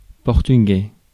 Ääntäminen
Synonyymit ibérique lusitanien langue de Camões Ääntäminen France: IPA: [pɔʁ.ty.ɡɛ] Tuntematon aksentti: IPA: /pɔʁ.ty.gɛ/ Haettu sana löytyi näillä lähdekielillä: ranska Käännös Ääninäyte Adjektiivit 1.